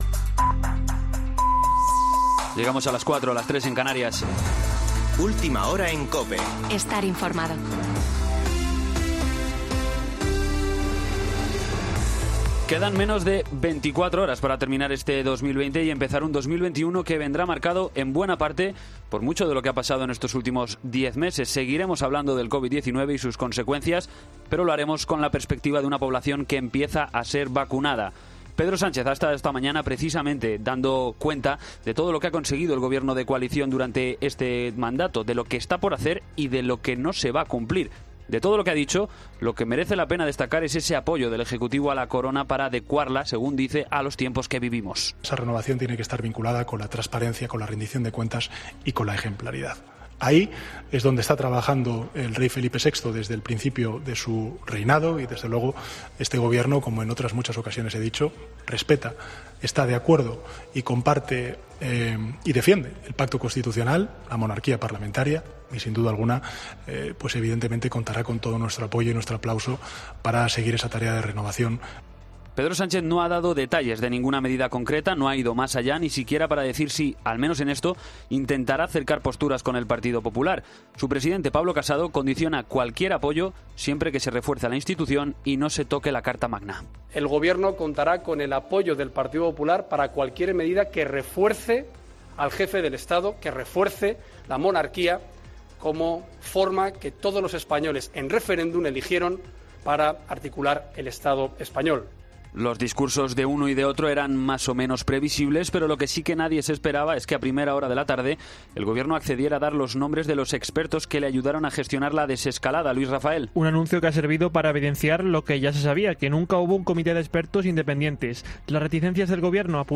Boletín de noticias COPE del 30 de diciembre de 2020 a las 04.00 horas